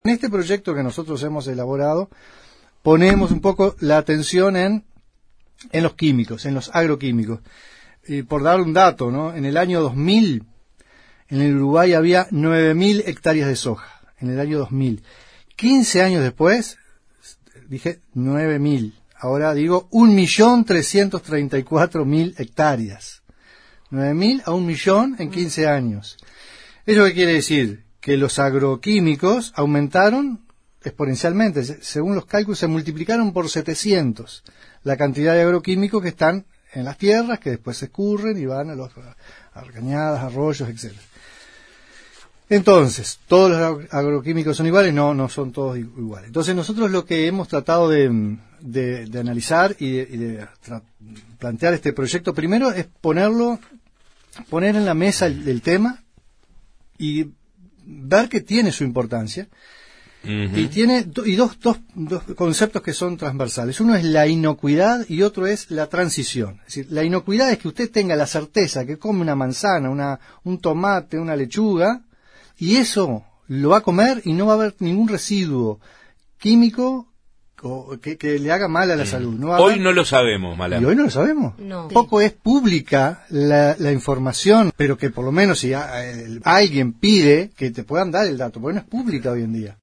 Entrevista con el diputado frenteamplista por Soriano, Enzo Malán